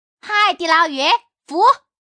Index of /poker_paodekuai/update/1527/res/sfx/changsha_woman/